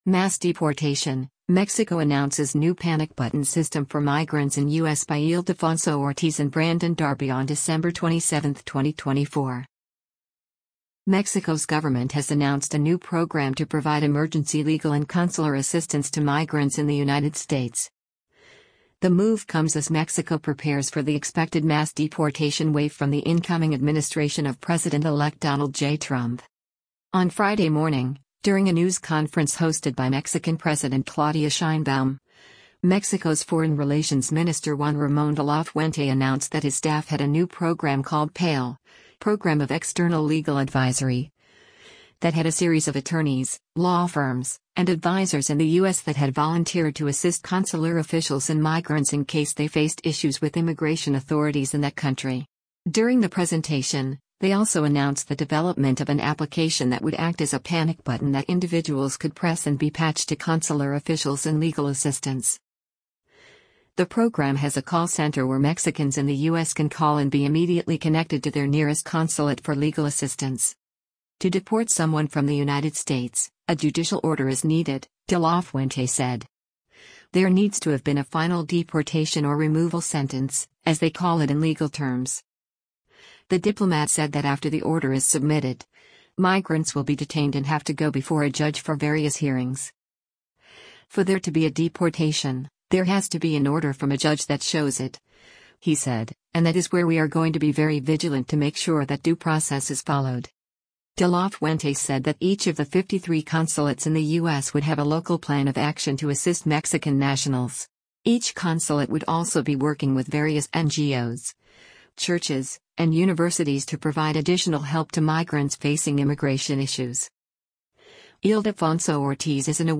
Mexican President Claudia Sheinbaum and her diplomatic staff announce a new program for he
On Friday morning, during a news conference hosted by Mexican President Claudia Sheinbaum, Mexico’s Foreign Relations Minister Juan Ramon De La Fuente announced that his staff had a new program called PALE (Program of External Legal Advisory) that had a series of attorneys, law firms, and advisors in the U.S. that had volunteered to assist consular officials and migrants in case they faced issues with immigration authorities in that country.